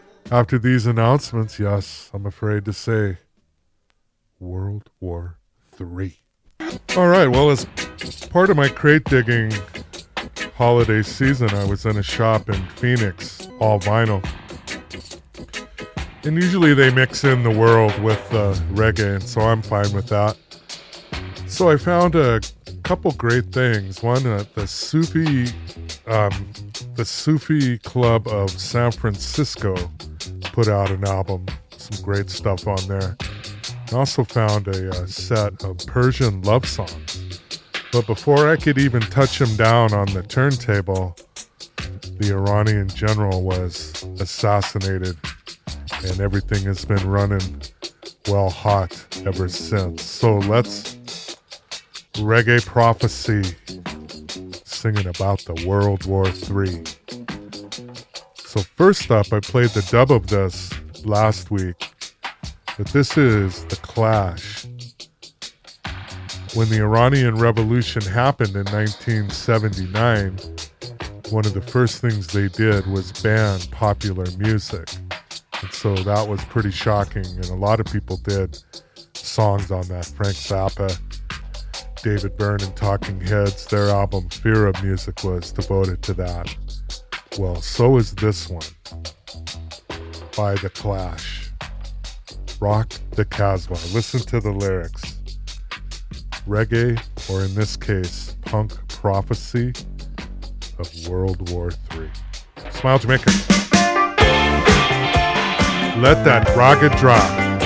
Reggae/Punk/Ska